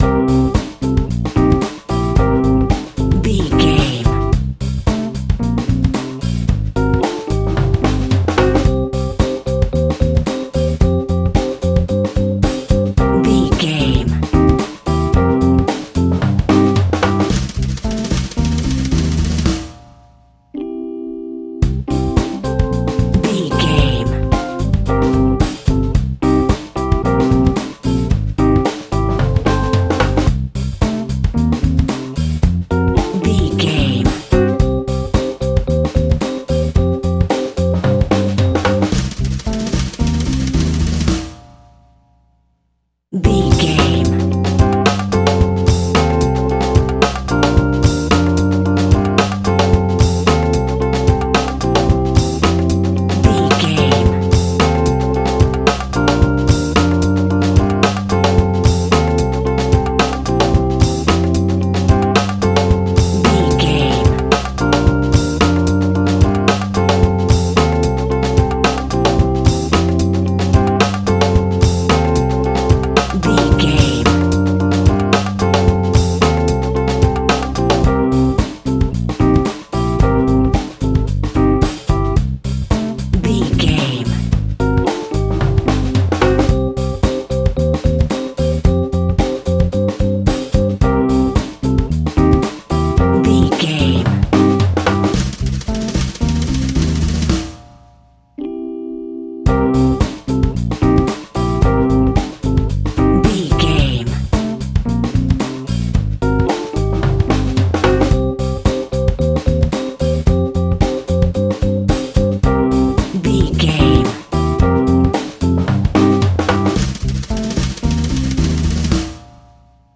Aeolian/Minor
groovy
lively
electric guitar
electric organ
bass guitar
saxophone
percussion